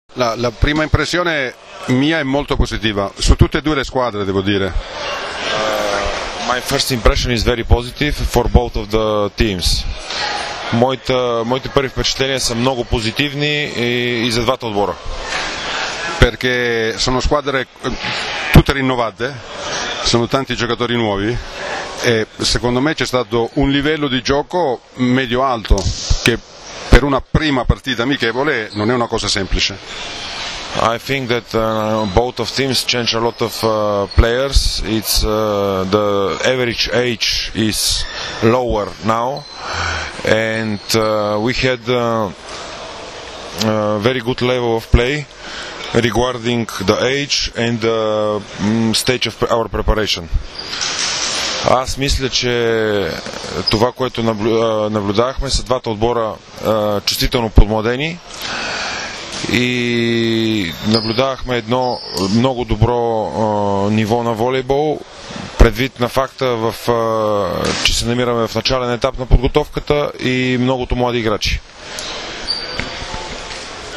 IZJAVA KAMILA PLAĆIJA